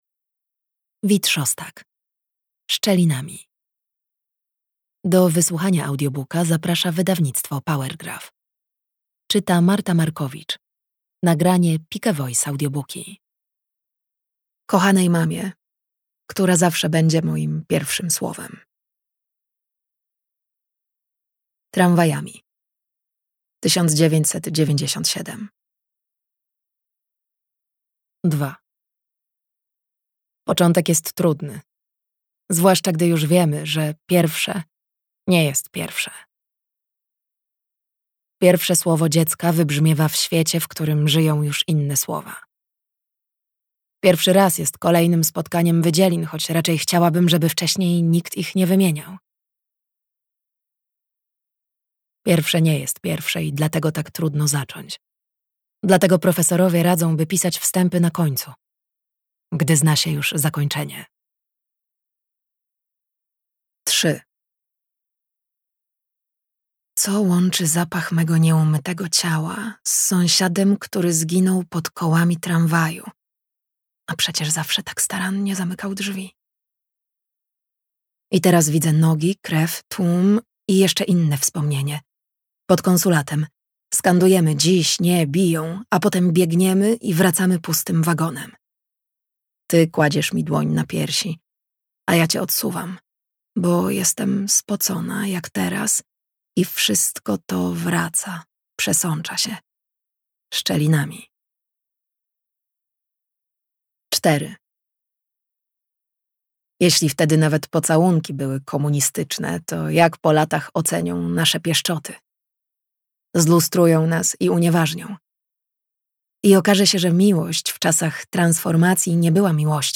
Szczelinami - Wit Szostak - audiobook + książka